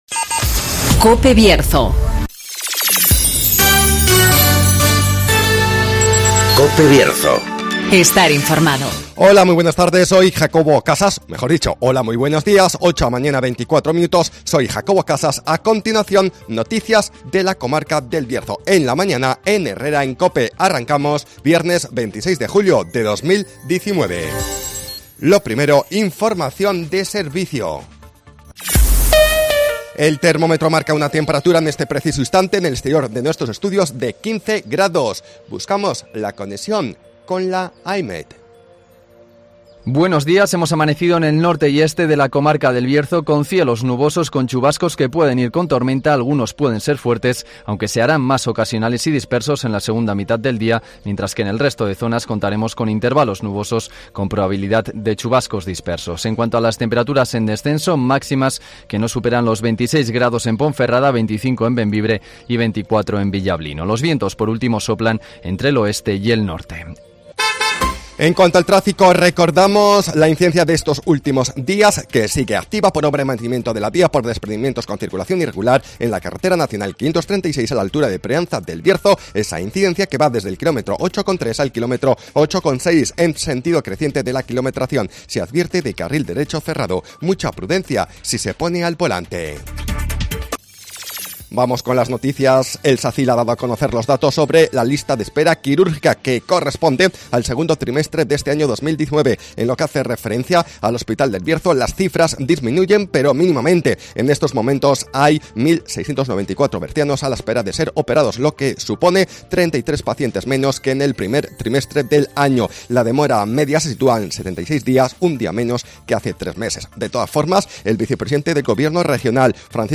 INFORMATIVOS BIERZO
Conoce las noticias de las últimas horas de nuestra comarca, con las voces de los protagonistas